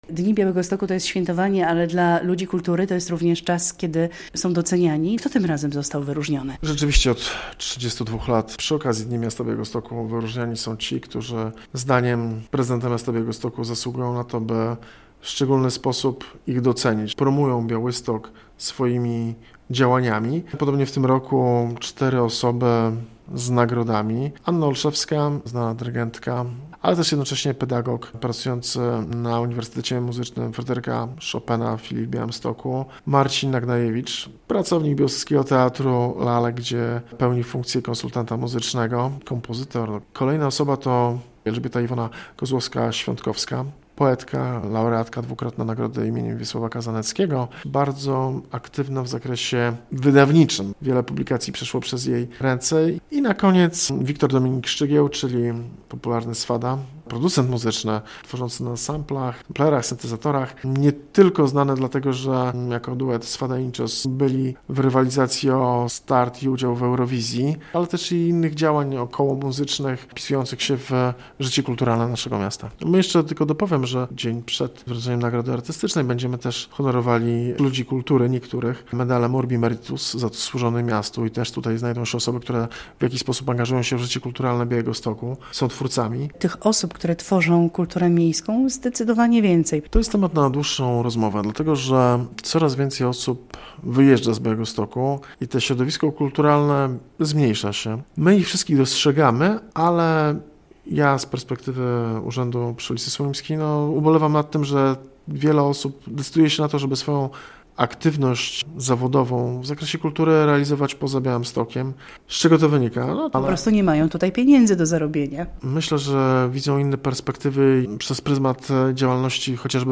Z zastępcą prezydenta Białegostoku Rafałem Rudnickim rozmawia